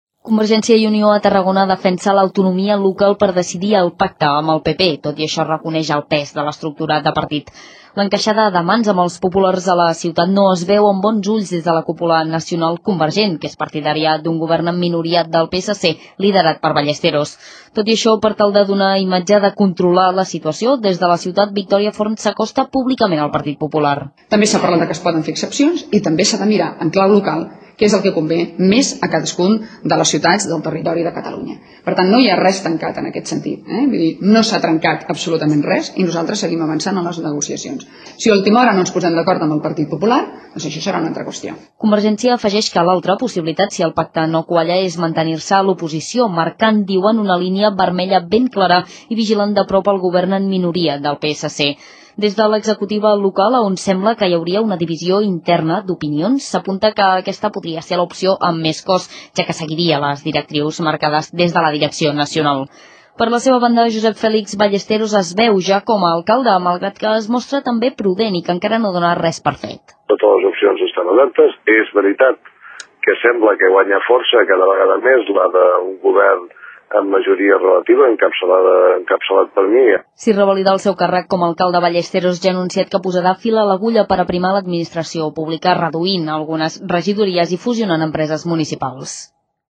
Informatius: crònica municipal - SER Tarragona, 2011